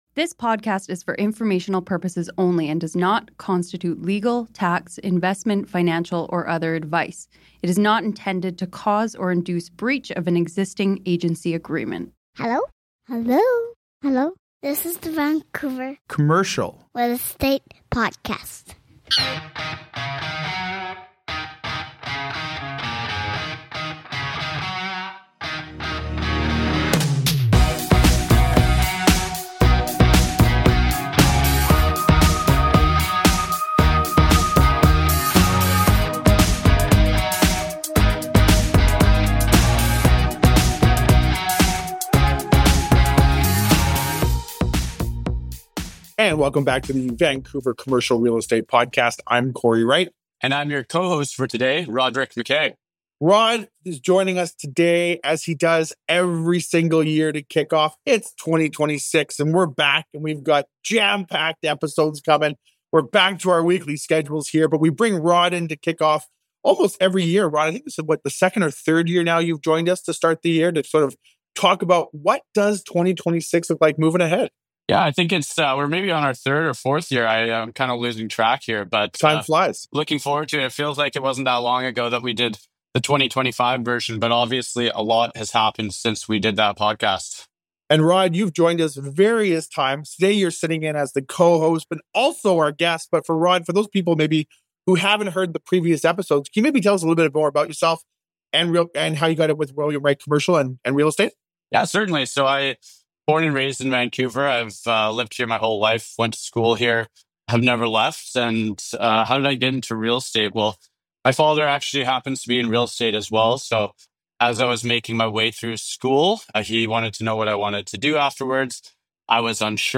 A timely, straight-shooting discussion for anyone trying to make sense of today’s market.